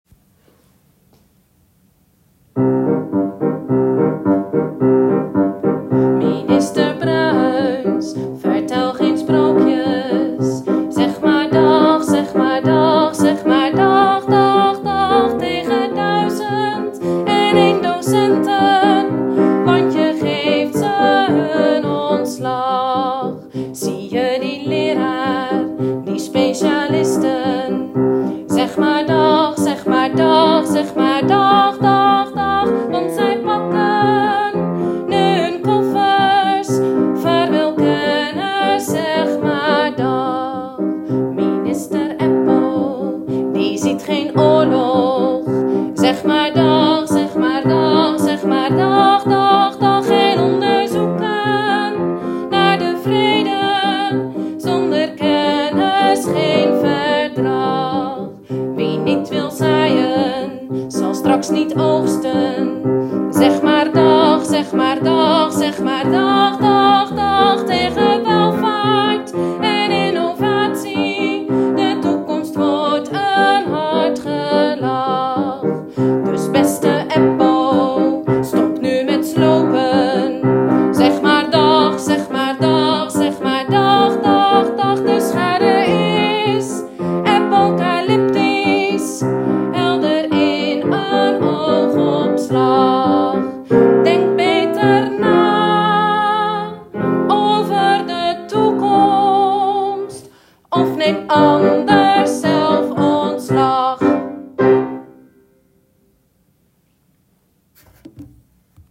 In de Wageningse WhatsApp-groep die voor het protest in het leven is geroepen, worden onder meer protestliederen gedeeld om tijdens de walk-out
Protestlied-Bella-Ciao.m4a